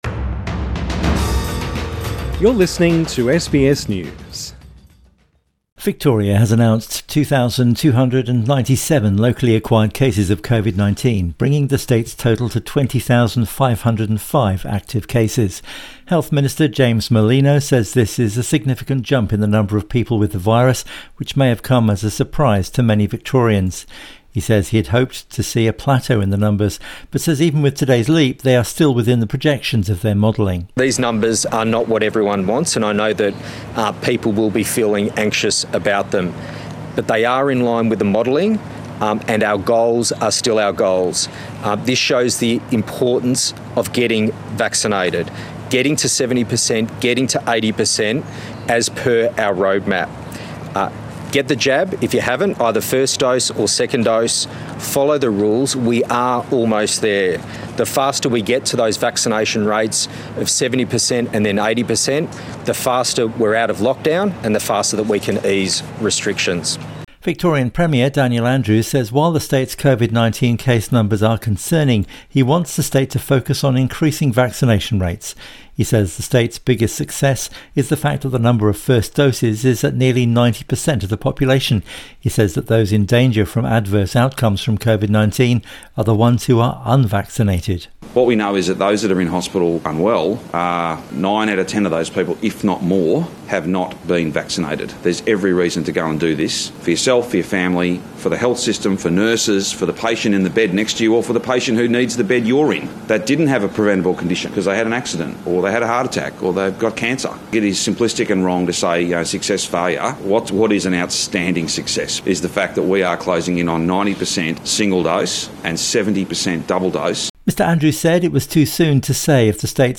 James Merlino speaks to the media in Melbourne Source: AAP